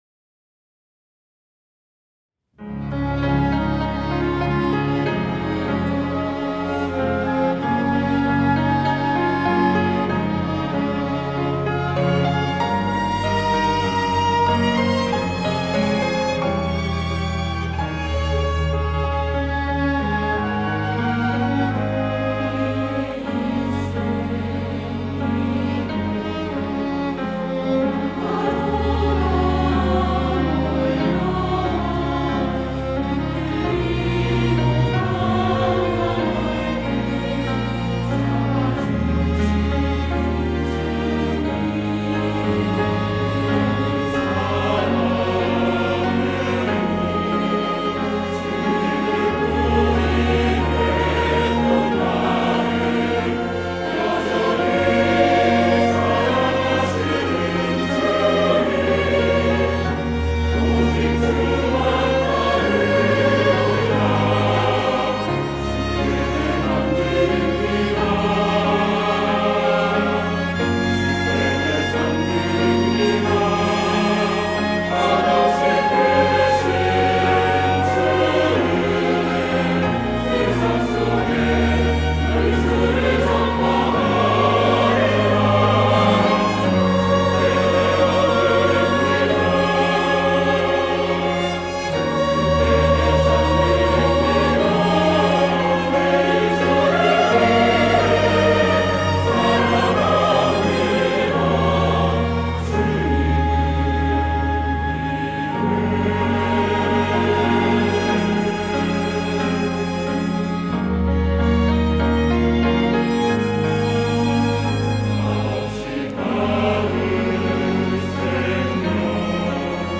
호산나(주일3부) - 주님을 위해
찬양대